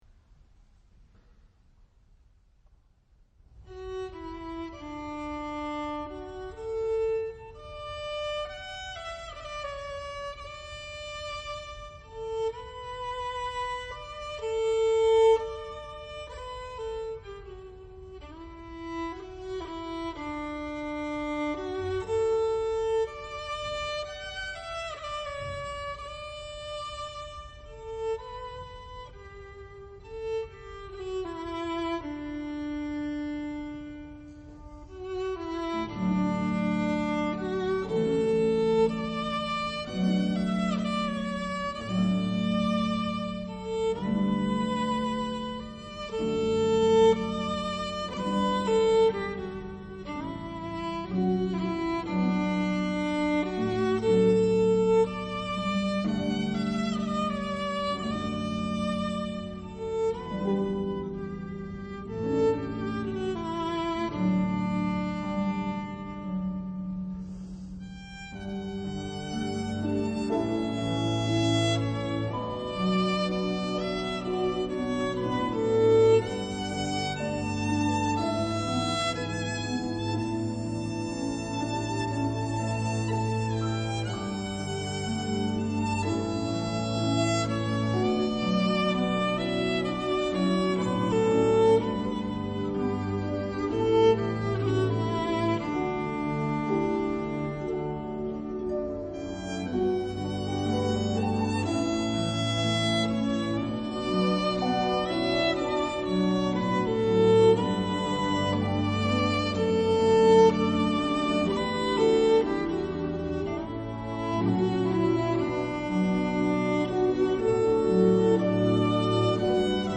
Shetland air
at The Symphony Hall 2nd Feb. 2006